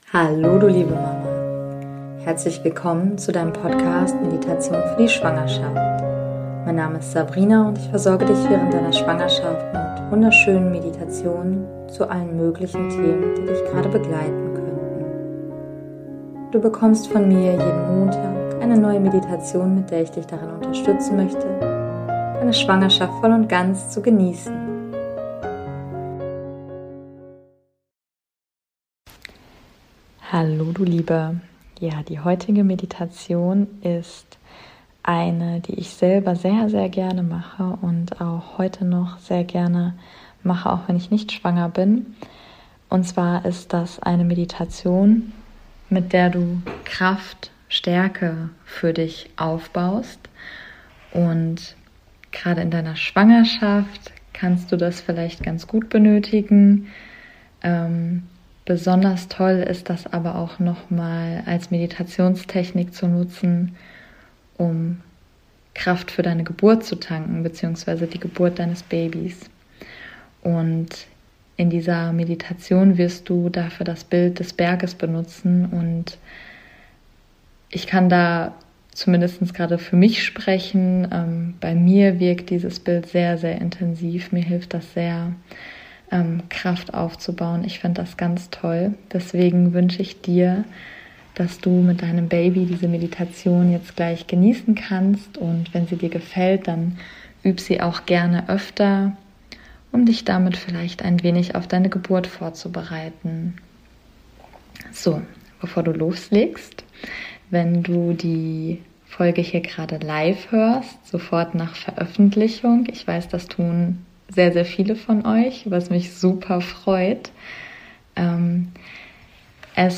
#029 - Meditation um Kraft für die Geburt aufzubauen ~ Meditationen für die Schwangerschaft und Geburt - mama.namaste Podcast